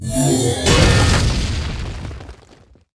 ram.ogg